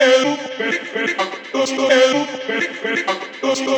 House Free sound effects and audio clips
• chopped vocals ping pong house delayed (12) - Dm - 127.wav
chopped_vocals_ping_pong_house_delayed_(12)_-_Dm_-_127_ZXZ.wav